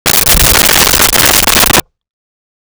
Creature Snarl 04
Creature Snarl 04.wav